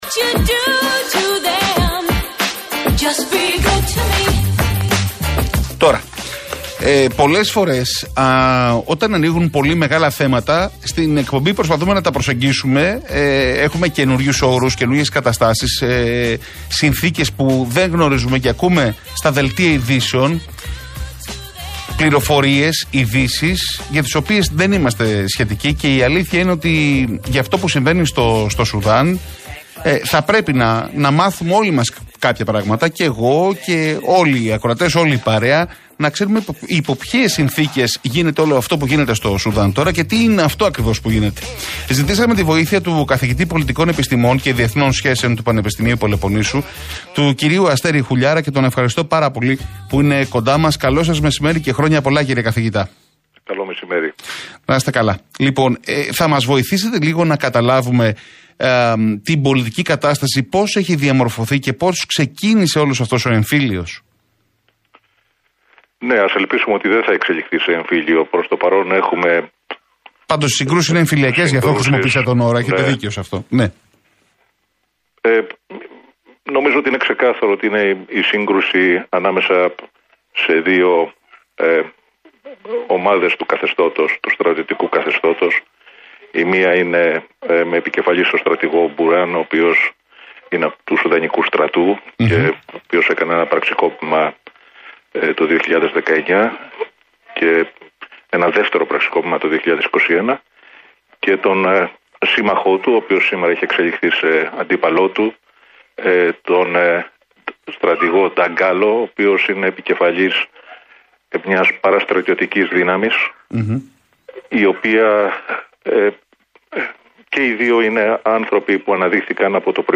μιλώντας στον Realfm 97,8